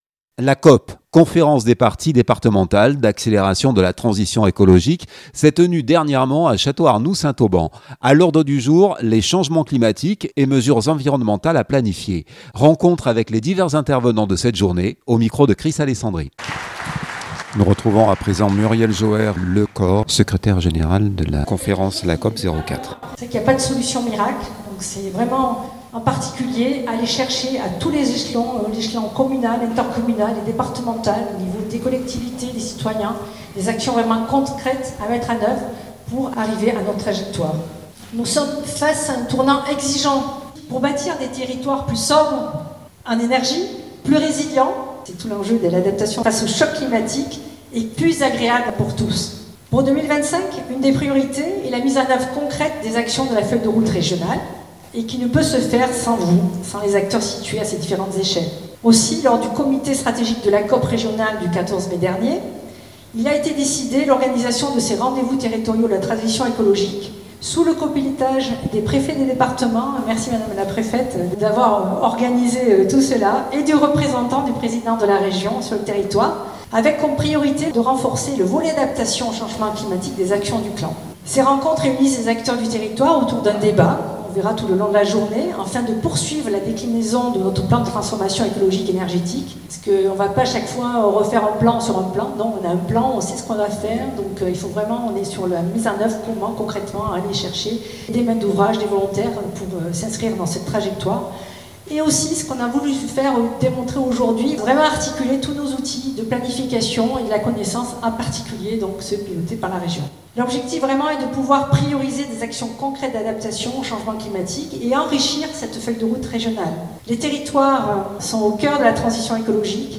A l’ordre du jour: les Changements climatiques et mesures environnementales à planifier. Rencontre avec les divers intervenants de cette journée